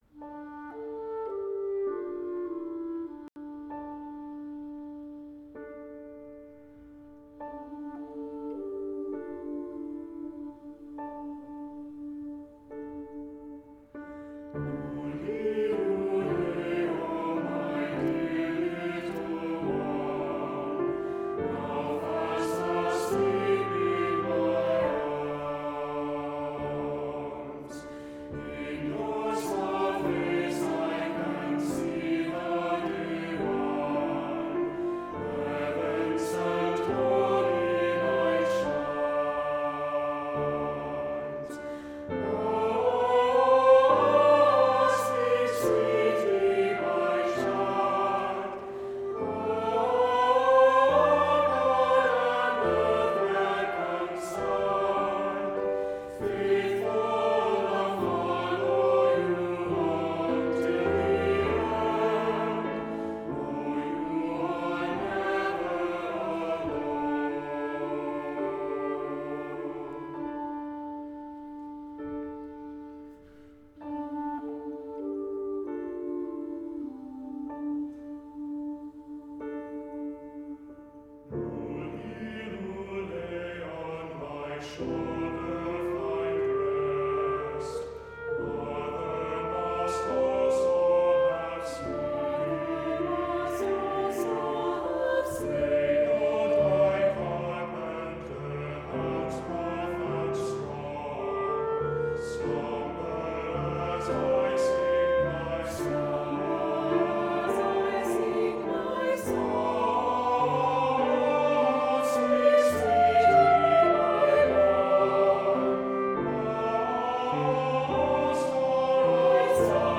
Voicing: "SATB","Solo","Assembly"